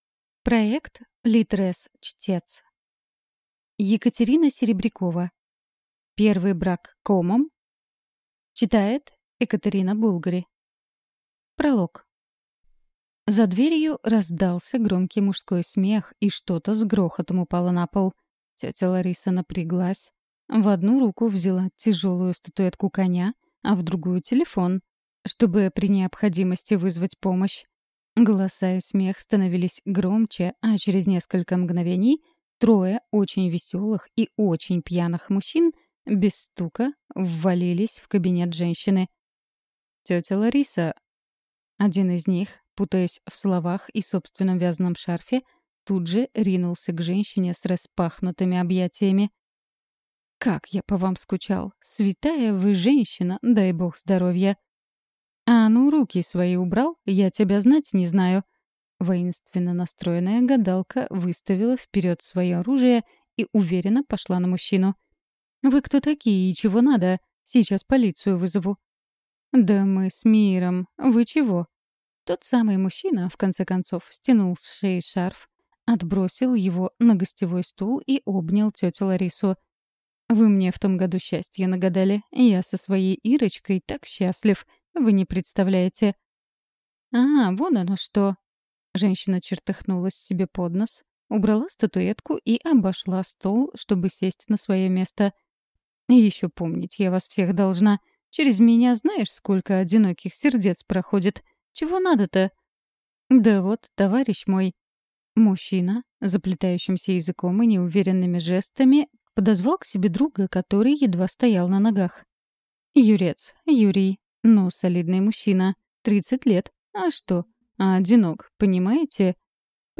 Аудиокнига «О людях и чудовищах».